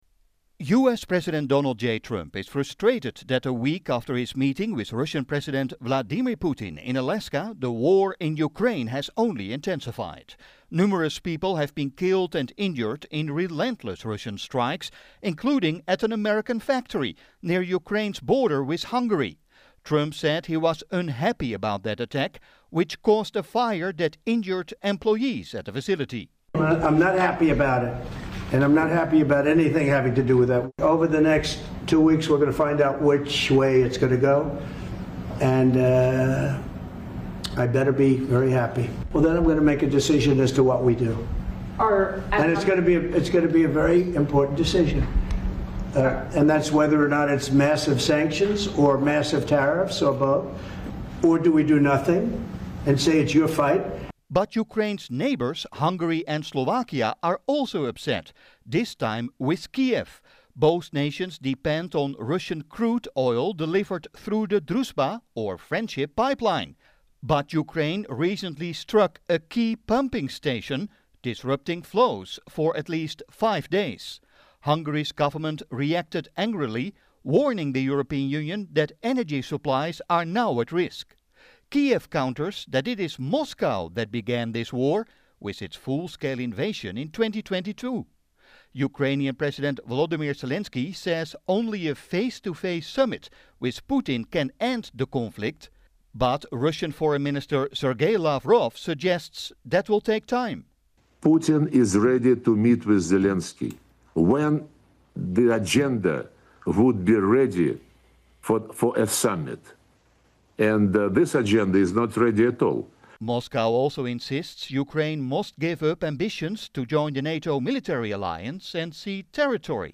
WorthyNewsRadioAug23.mp3